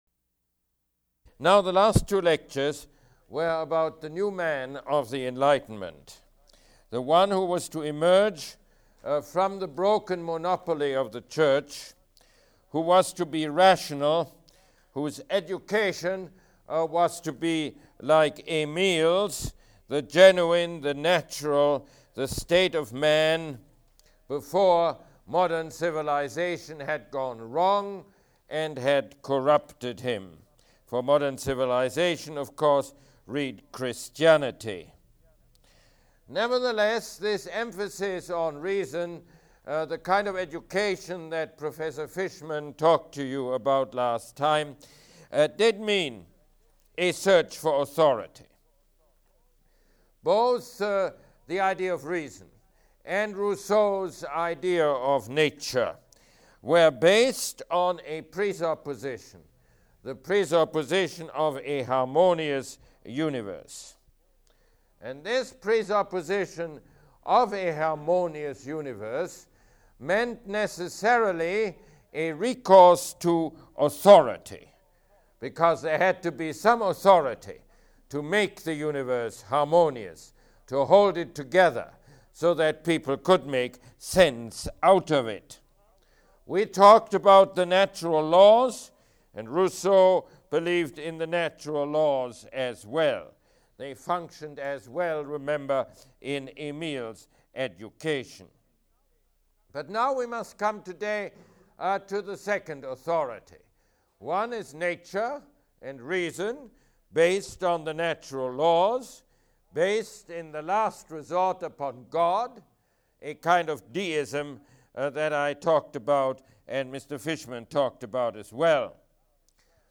Mosse Lecture #7